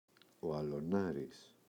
αλωνάρης, ο [aloꞋnaris]